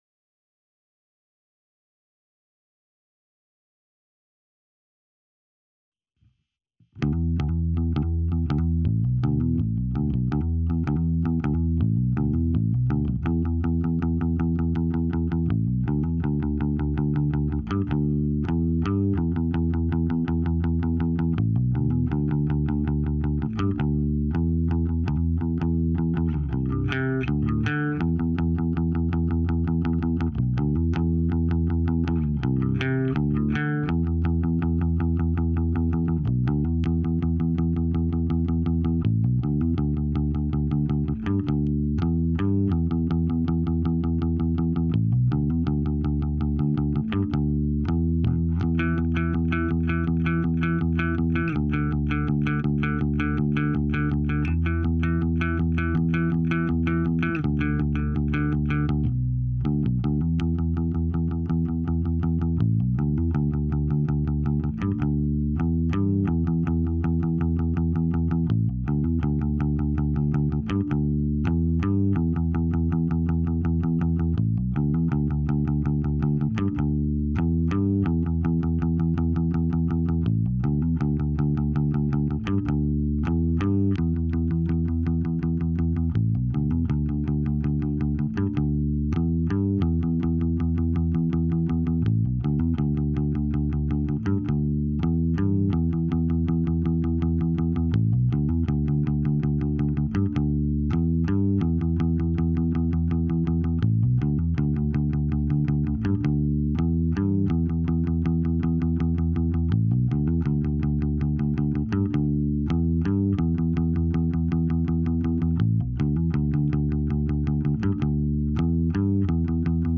with the lead guitars removed